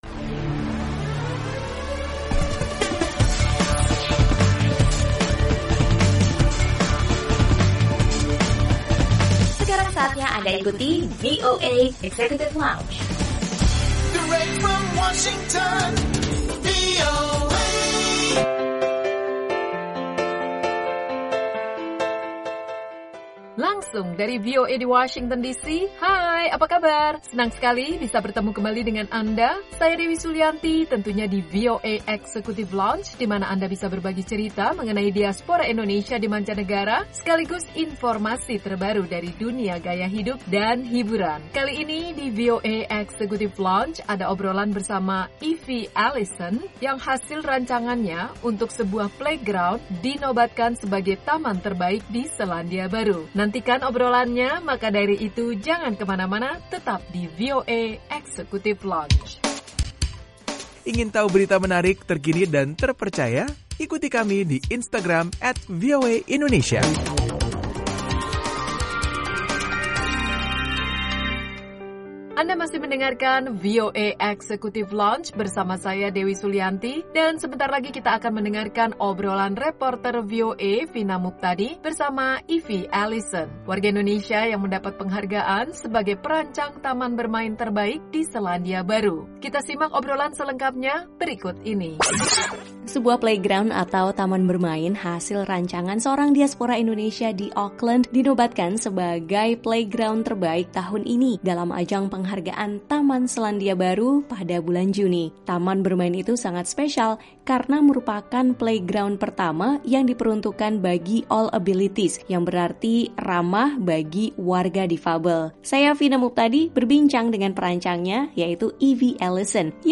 Obrolan reporter